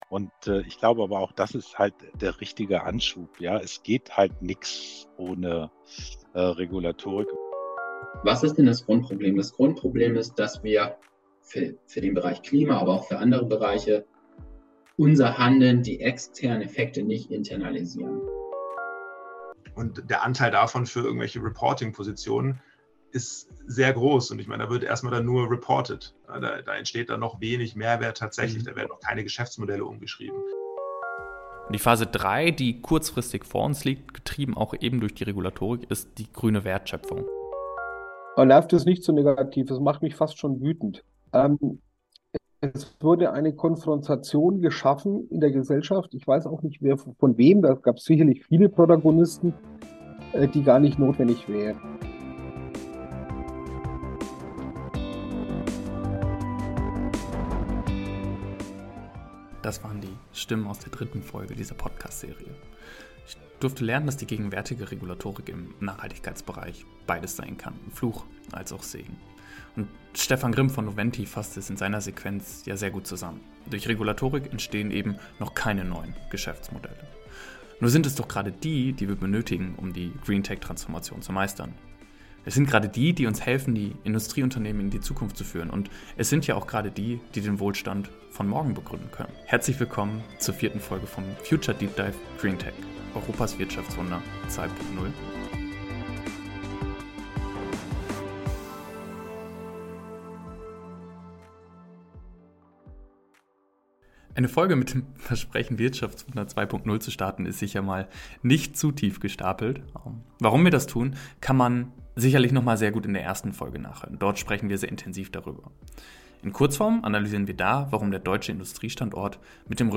Wir möchten Licht ins Dunkle bringen und über die nachhaltige Transformation in industriellen Unternehmen aufklären. Diese Serie besteht aus fünf Folgen für die verschiedenste, spannende Menschen interviewt wurden.